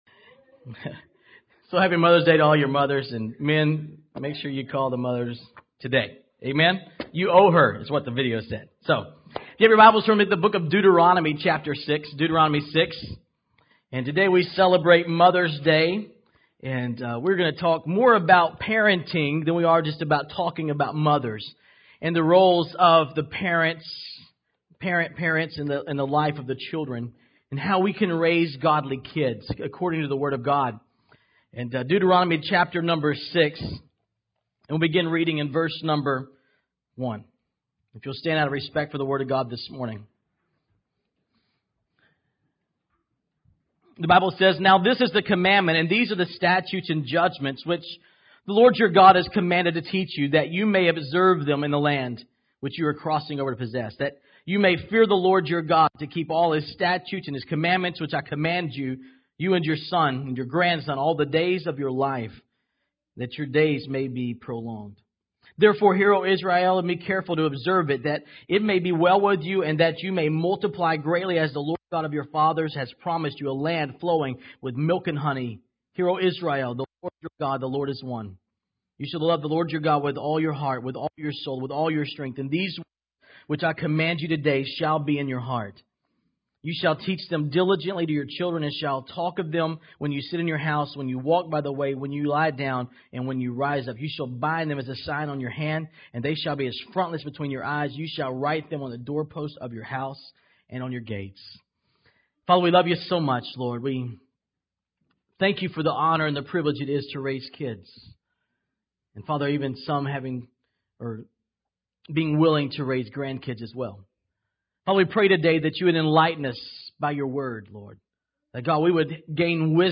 Here is Sunday’s message. Instead of the traditional mother’s day message, I shared basic parenting principles, not just for Mothers but for the Dads as well.